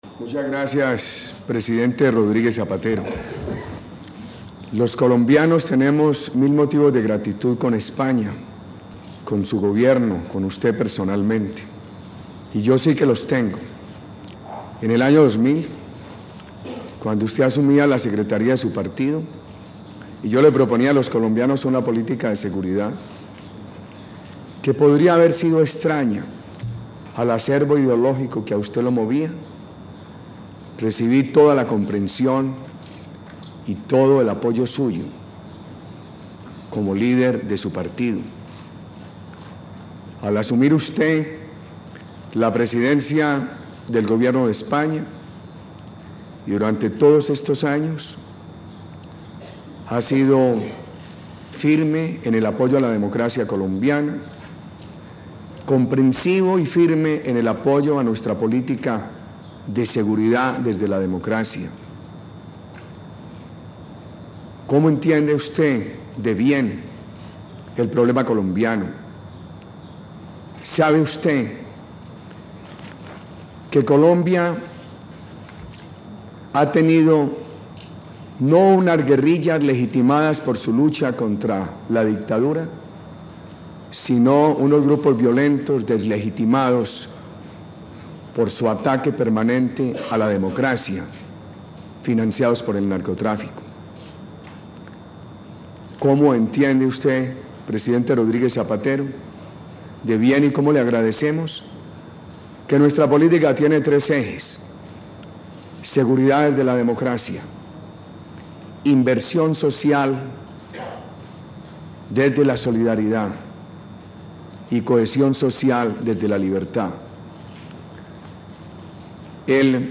Declaración del Presidente Uribe luego de su encuentro con el Presidente del Gobierno de España, José Luis Rodríguez Zapatero
Madrid, 23 de enero de 2008